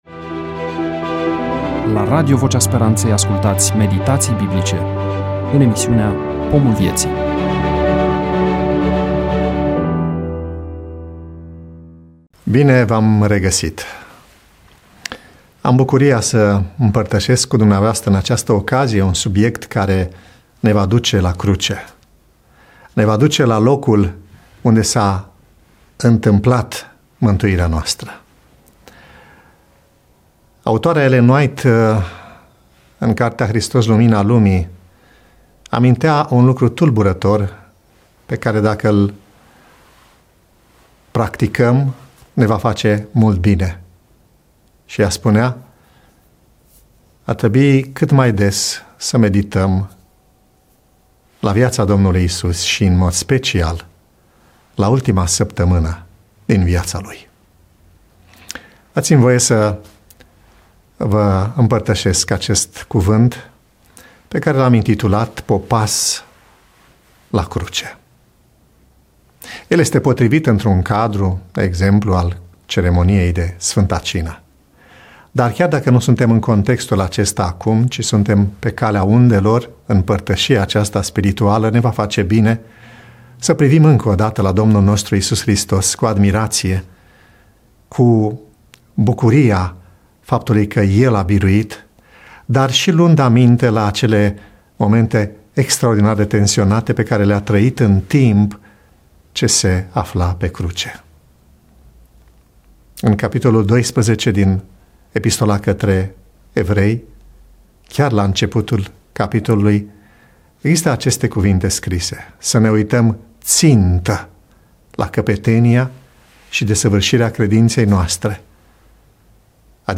EMISIUNEA: Predică DATA INREGISTRARII: 15.01.2026 VIZUALIZARI: 14